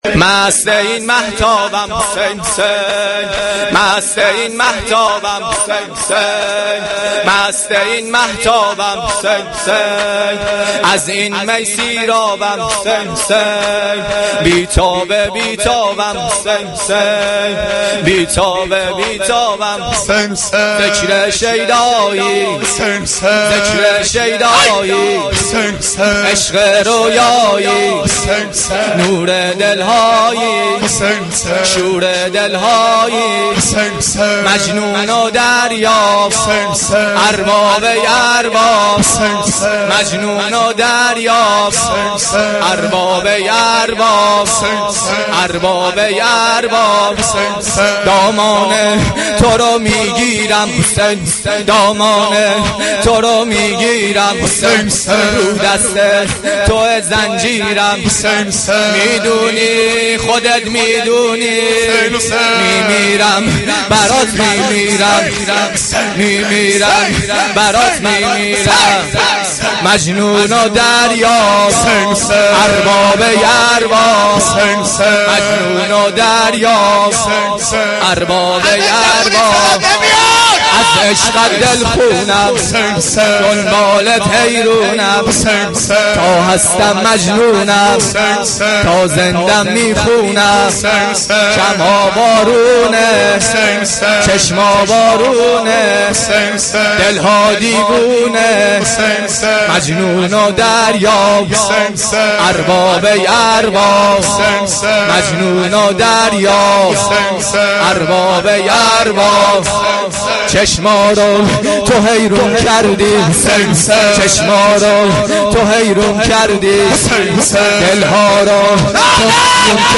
مداحی
Shab-5-Moharam-5.mp3